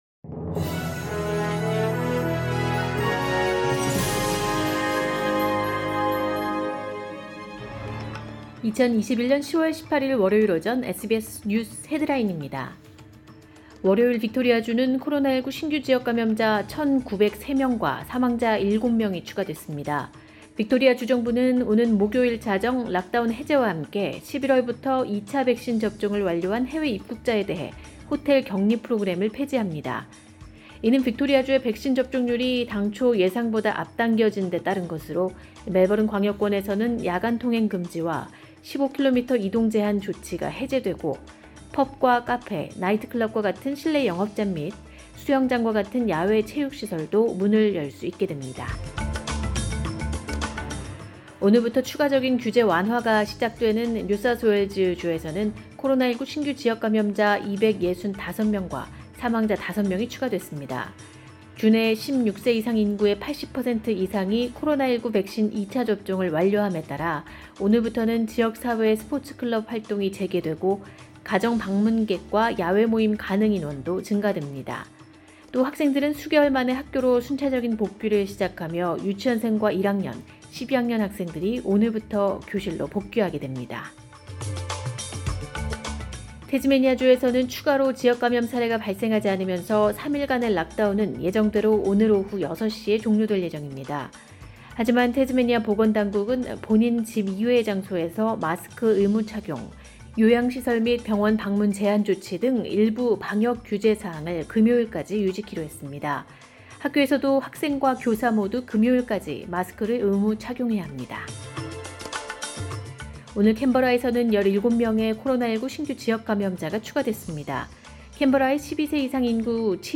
2021년 10월 18일 월요일 오전의 SBS 뉴스 헤드라인입니다.